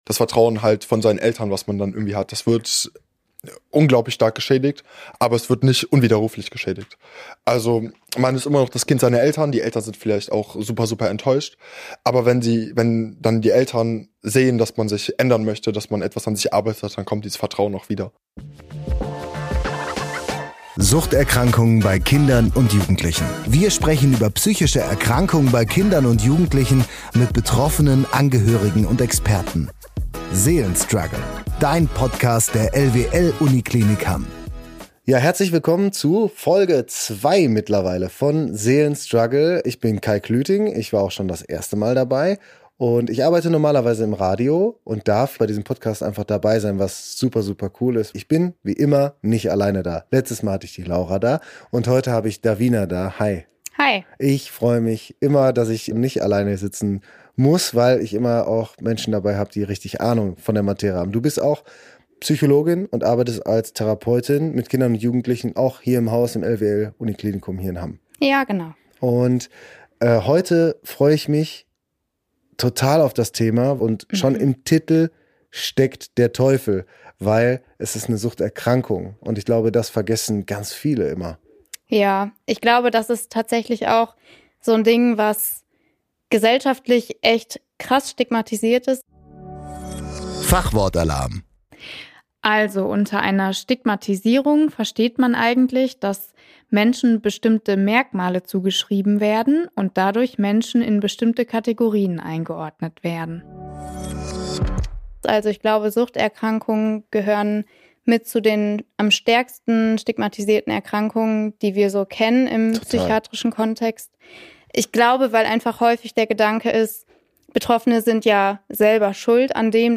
Unsere Gäste erzählen ihre Geschichten: von ersten Erfahrungen, bis hin zu dem Moment, als sie realisiert haben, dass sie Hilfe brauchen. Wir reden offen darüber, wie Sucht sich auf das Leben auswirkt und was das für den Alltag bedeutet.